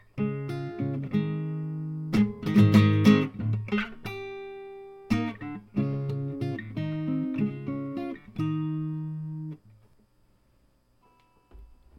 This time, I got respectable sound.
Not as crisp as my $100 condenser mic, but good sound from $4 in magnets and scrap parts. There was also noticible 60 Hz hum and radio noise.
First aluminum leaf sample